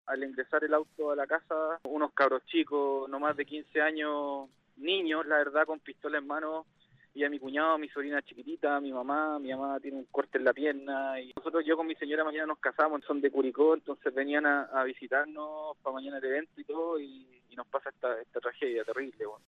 cu-testimonio-hijo-de-portonazo-maipu-ok-.mp3